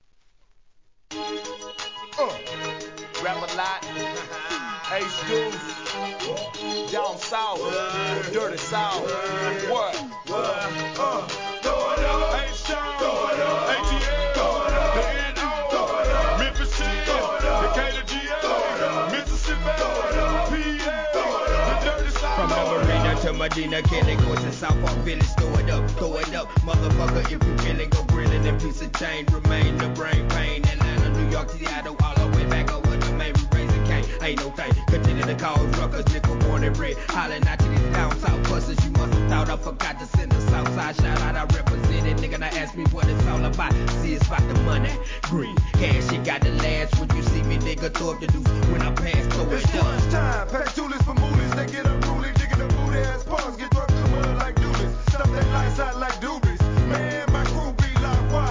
G-RAP/WEST COAST/SOUTH
DIRTY SOUTHコンピレーション!!